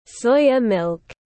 Sữa đậu nành tiếng anh gọi là soya milk, phiên âm tiếng anh đọc là /ˈsɔɪ.ə ˌmɪlk/